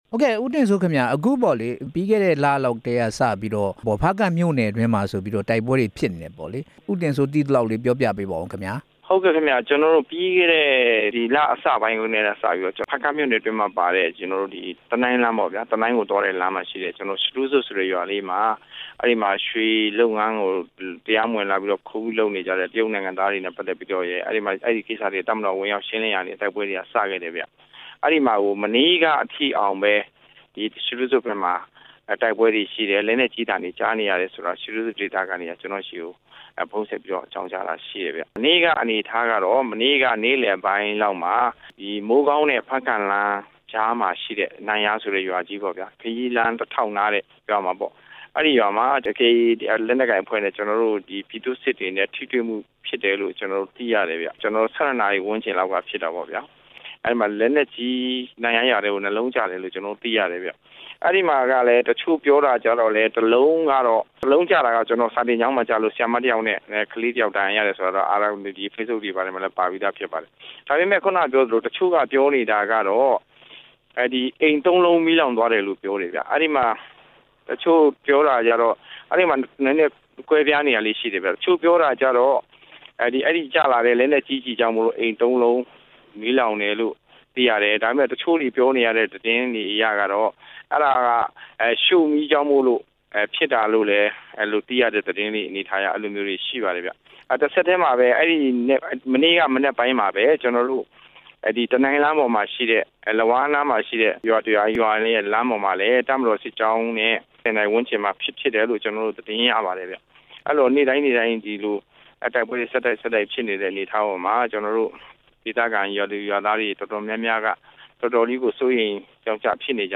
ဦးတင်စိုးနဲ့ မေးမြန်းချက်